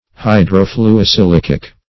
Search Result for " hydrofluosilicic" : The Collaborative International Dictionary of English v.0.48: Hydrofluosilicic \Hy`dro*flu`o*si*lic"ic\, a. [Hydro-, 2 + fluorine + silicic.]
hydrofluosilicic.mp3